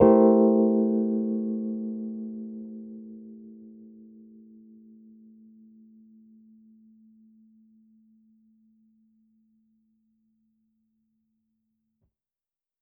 Index of /musicradar/jazz-keys-samples/Chord Hits/Electric Piano 3
JK_ElPiano3_Chord-Am7b9.wav